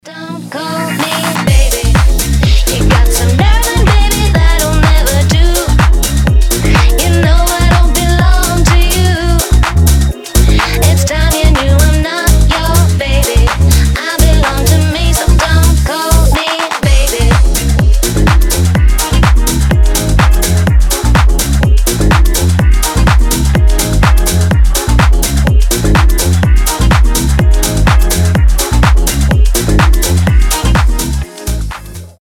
• Качество: 320, Stereo
диско
retromix
house